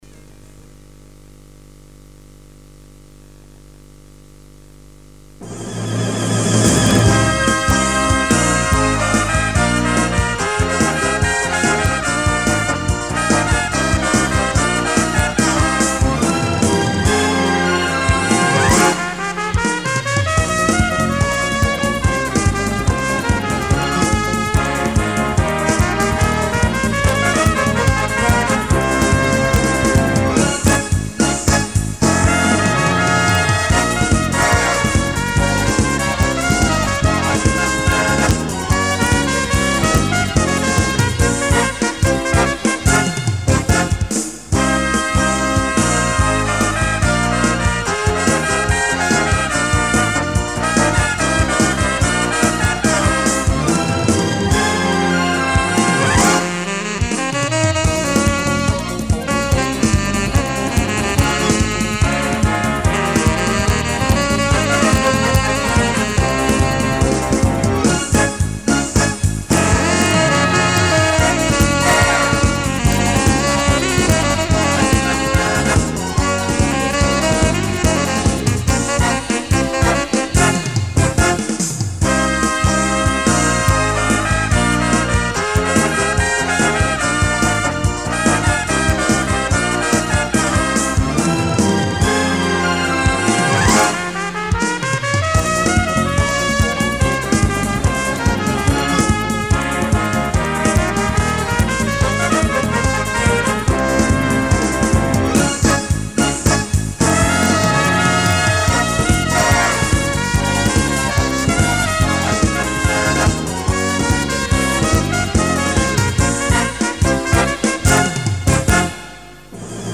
versione strumentale